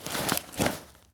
foley_sports_bag_movements_03.wav